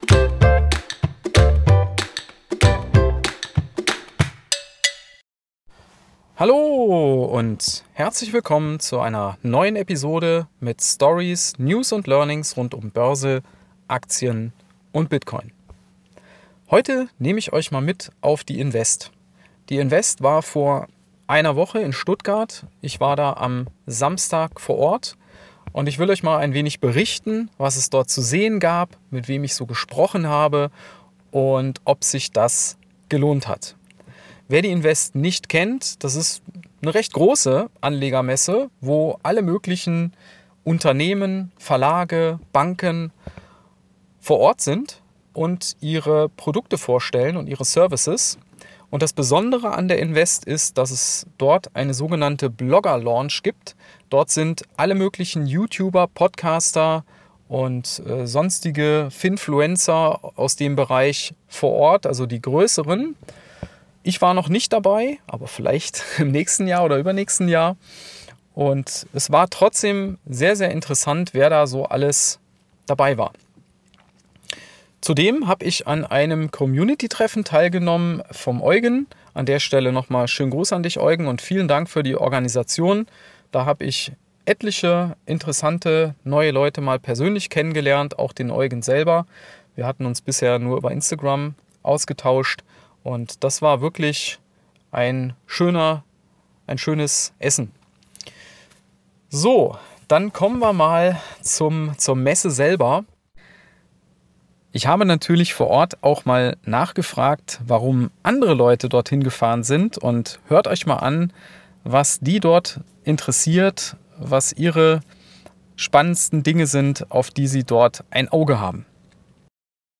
In der aktuellen Folge nehme ich euch mit auf die Invest in Stuttgart, eine Anlegermesse, die ich letzten Samstag besucht habe. Neben meinen persönlichen Eindrücken bekommt ihr auch ein paar interessante O-Töne von Besuchern vor Ort zu hören.